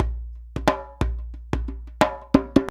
089DJEMB13.wav